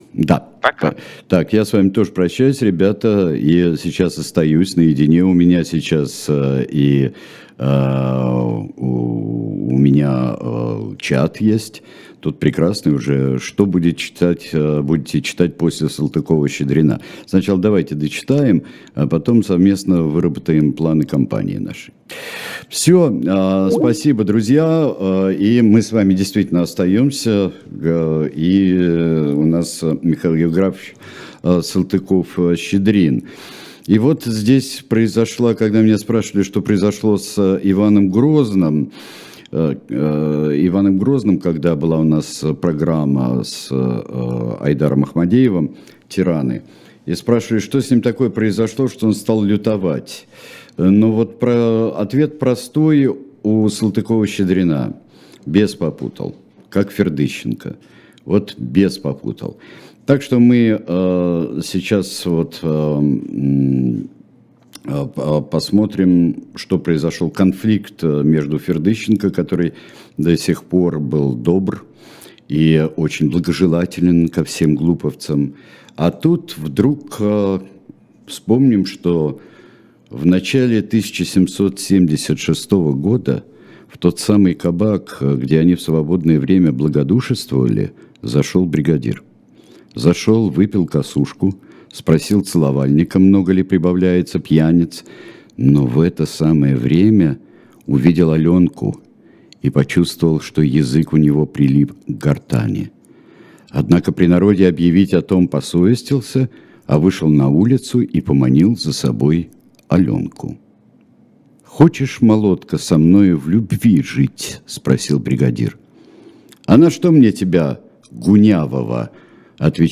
Сергей Бунтман читает книгу Салтыкова-Щедрина «История одного города»